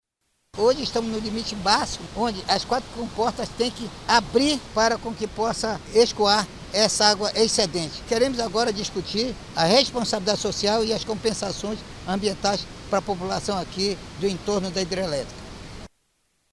Sonora-Sinesio-Campos-–-deputado-estadual.mp3